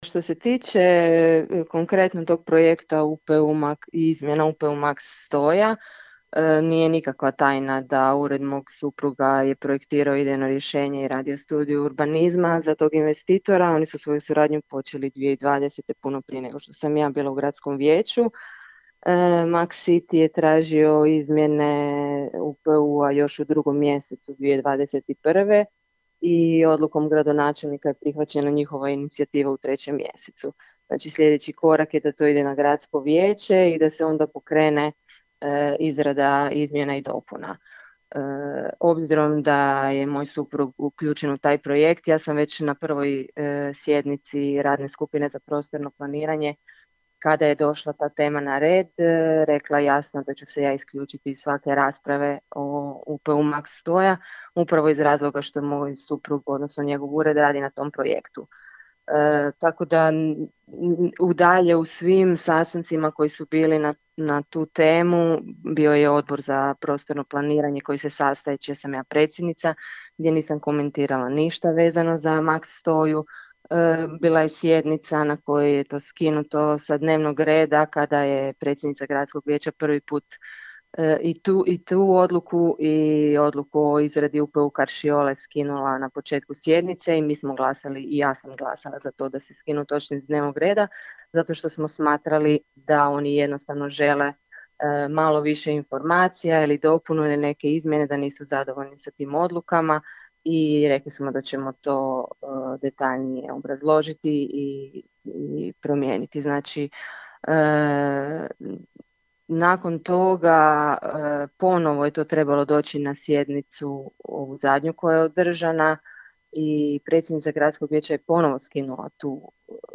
Audio izjava Ivane Mohorović za Radio Maestral